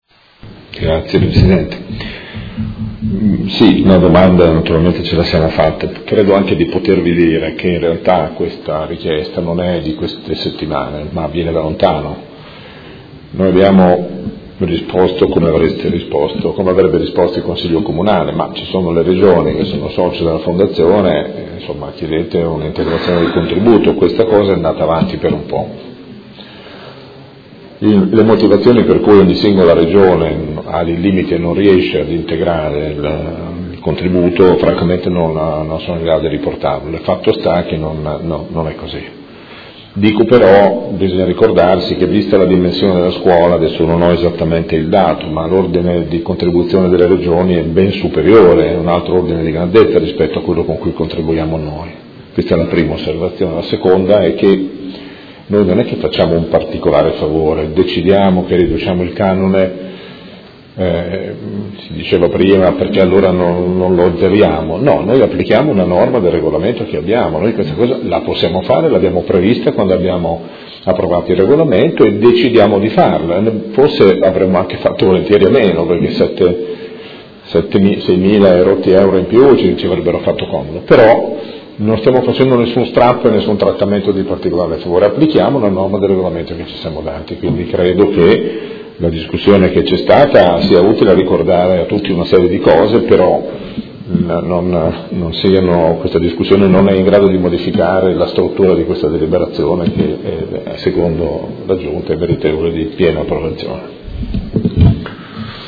Seduta del 20/07/2017 Replica a dibattito. Delibera. Immobile di Via Busani, 14 – Scuola Interregionale di Polizia Locale – Rideterminazione del corrispettivo ai sensi dell’art. 7.3 del Regolamento per la concessione in diritto di superficie